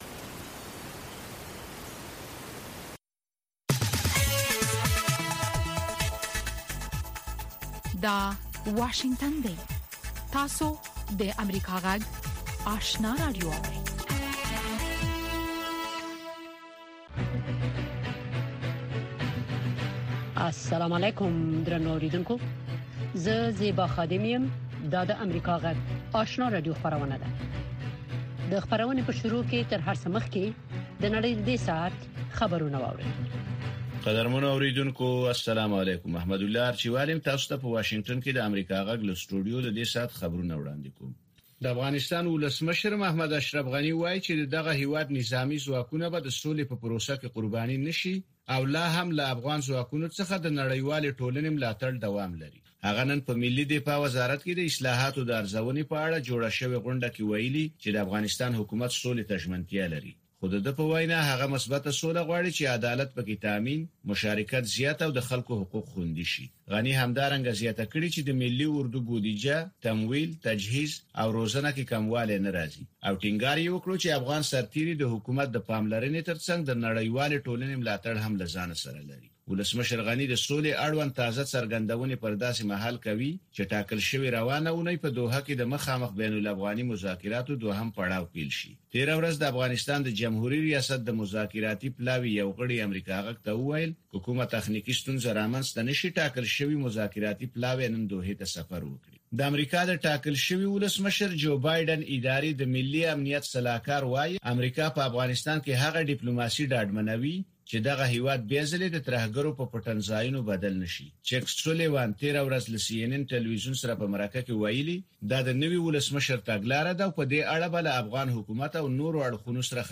لومړۍ ماښامنۍ خبري خپرونه